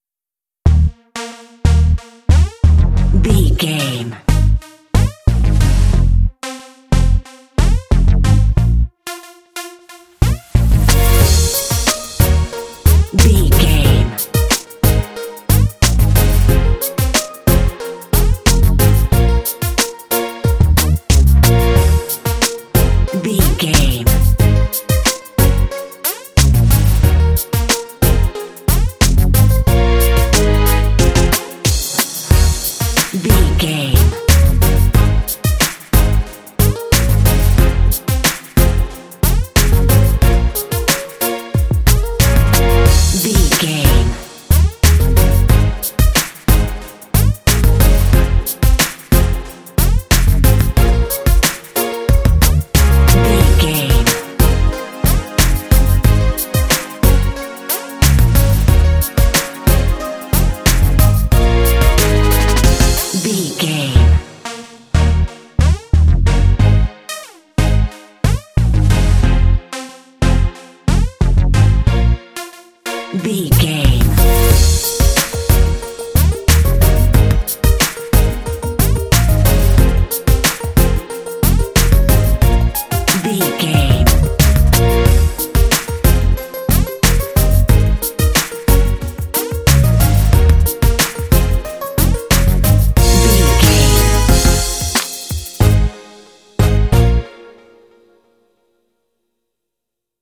Aeolian/Minor
B♭
urban
futuristic
synthesiser
bass guitar
drums
strings
synth-pop